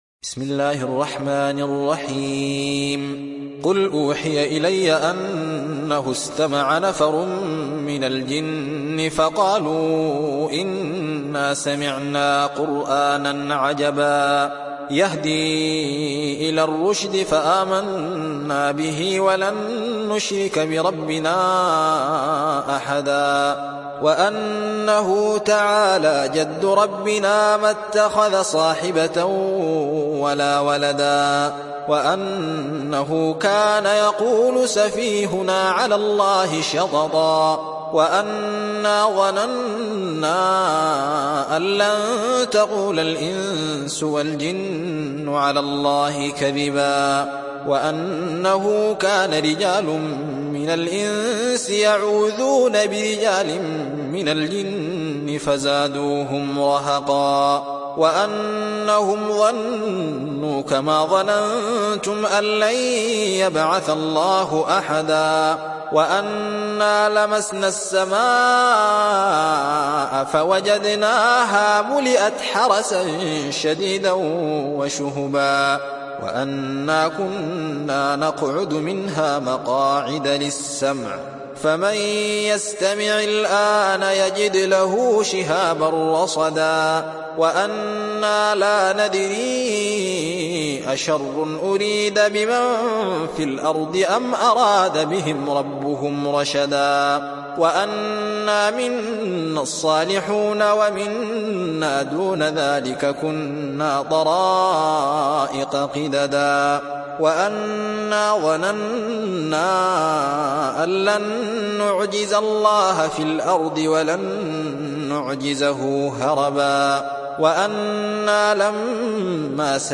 সূরা আল-জিন্ন ডাউনলোড mp3 Alzain Mohamed Ahmed উপন্যাস Hafs থেকে Asim, ডাউনলোড করুন এবং কুরআন শুনুন mp3 সম্পূর্ণ সরাসরি লিঙ্ক